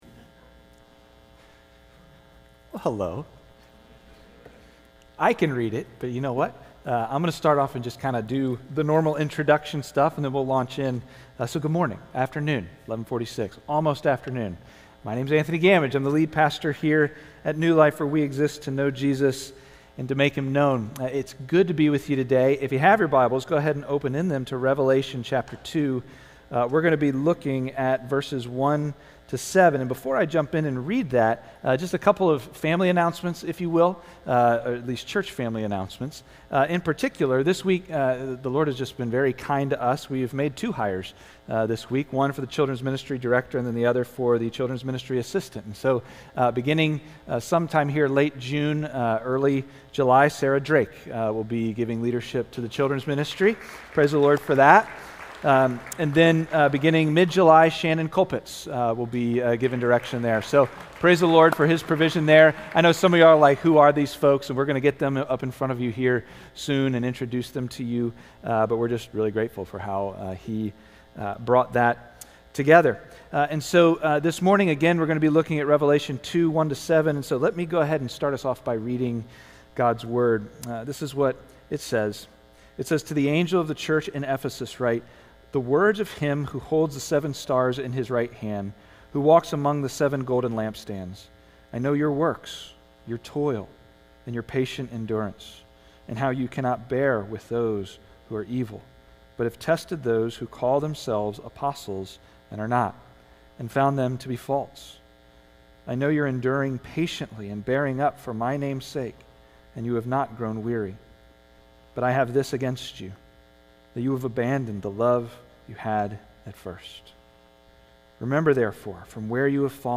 New Life Dresher (NLD) is a church whose purpose is to know Jesus and make Him known.